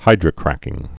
(hīdrə-krăkĭng)